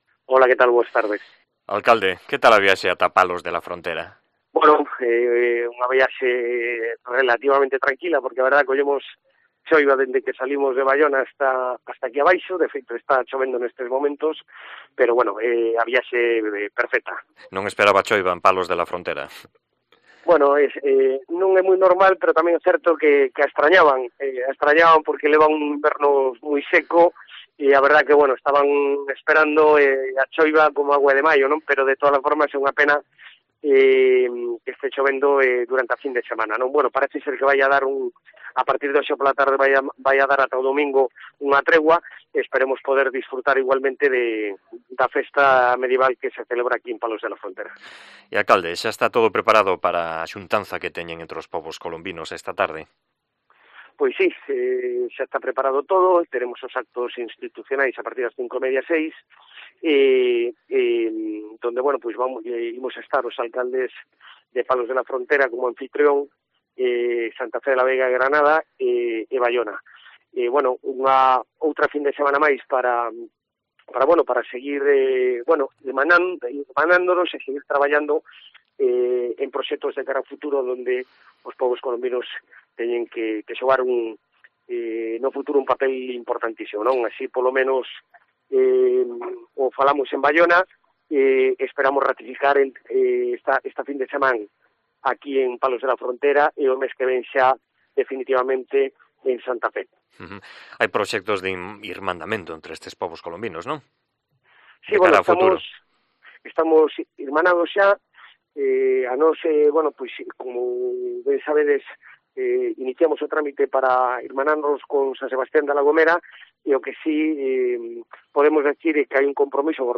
Entrevista con Carlos Gómez, alcalde de Baiona